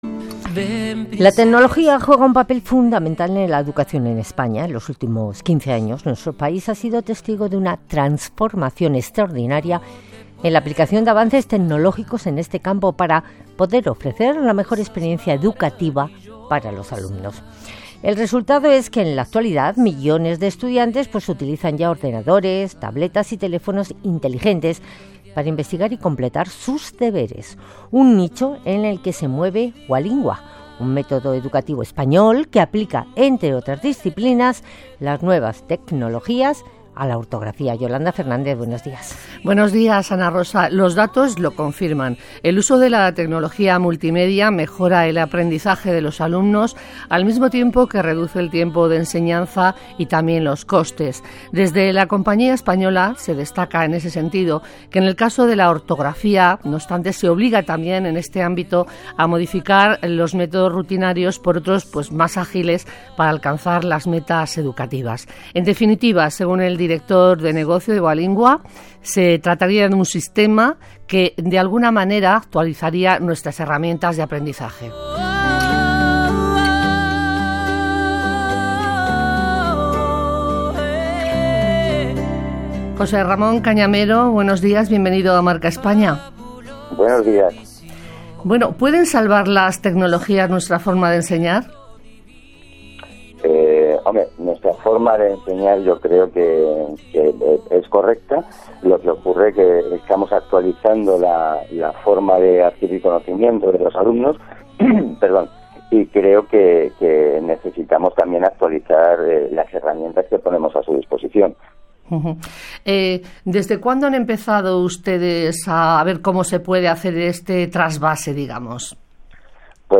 Entrevista en Radio Nacional de España (RNE)